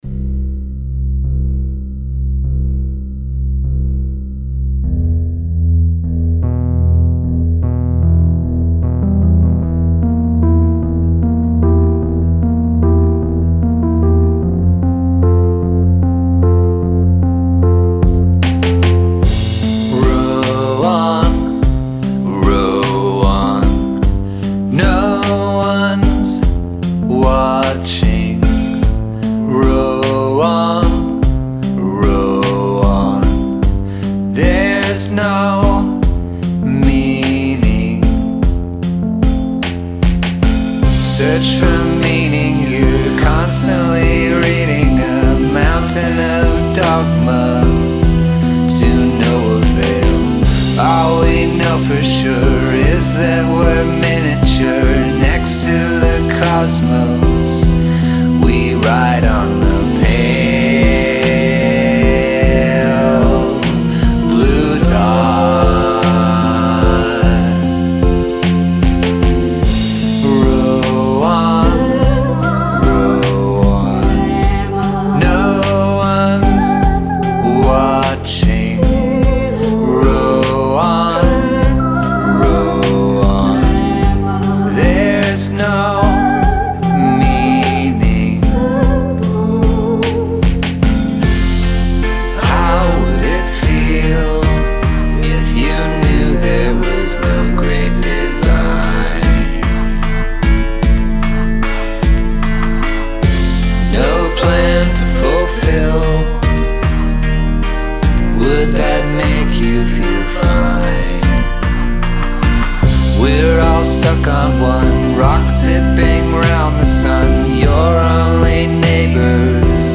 Must include a guest singing or speaking in another language
I love the female harmony vox.